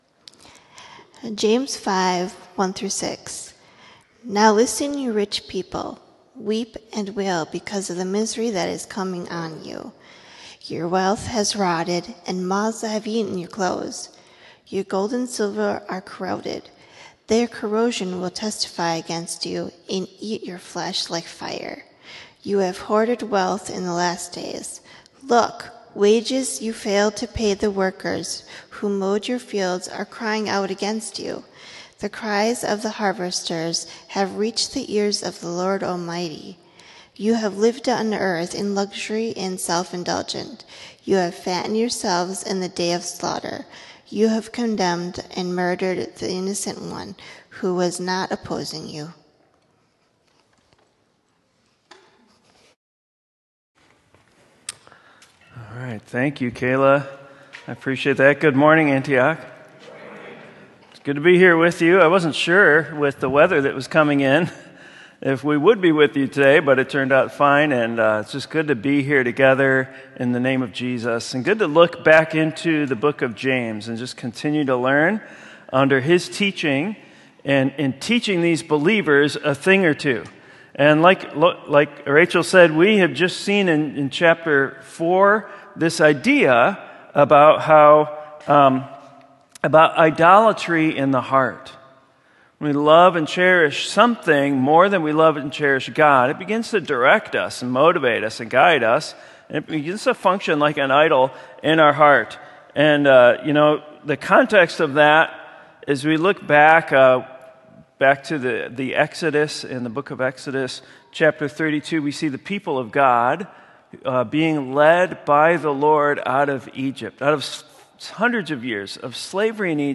Sermon: James: Heaven is for Later | Antioch Community Church - Minneapolis
sermon-james-heaven-is-for-later.m4a